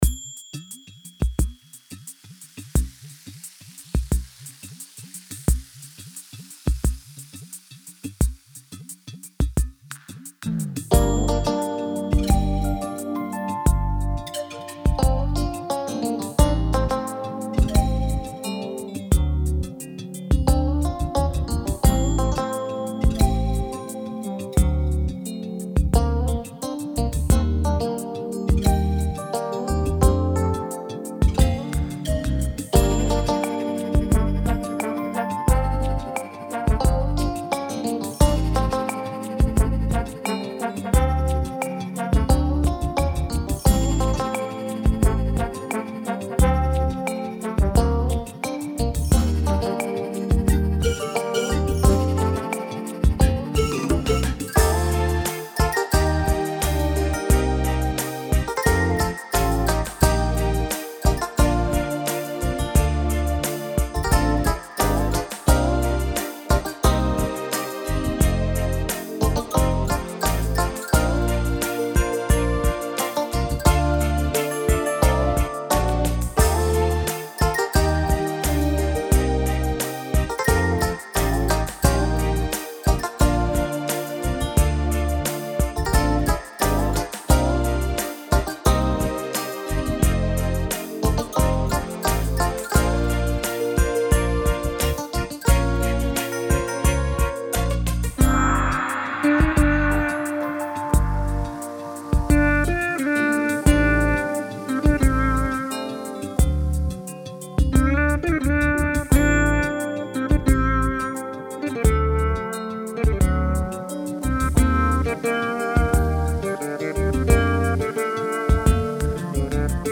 Привет всем..Нашёл старые диски акаевские..конвертнул в халеон... побаловался ...сводил в ушах akg-получился кабак...ну просто yamaha sy 77 ...vst вариант:)